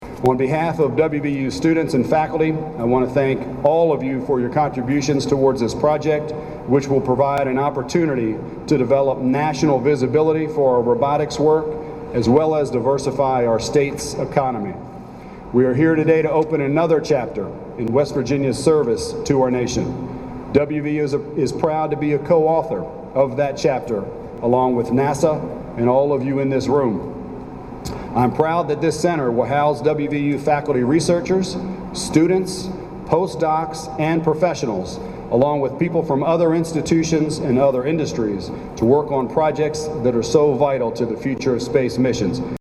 Click below to hear James P. Clements talk about WVU's involvement in the WVU-NASA Robotics Center.